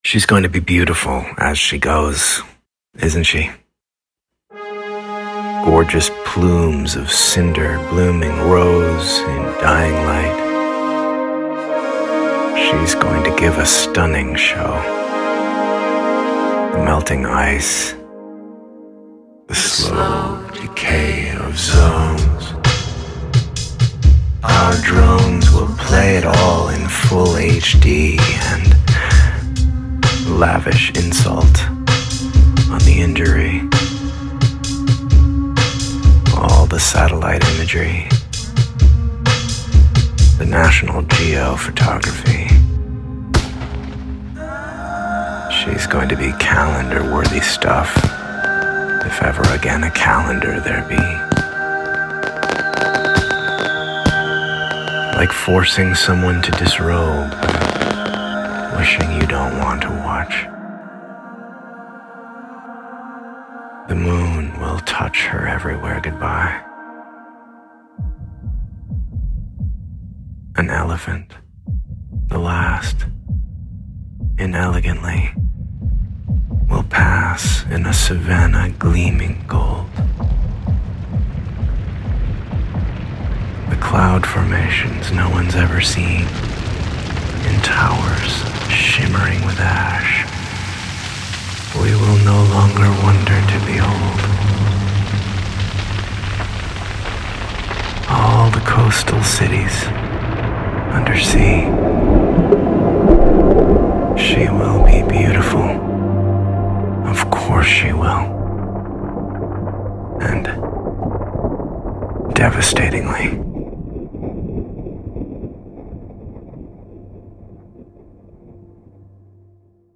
Letter to America: Poem
Music track with audio reading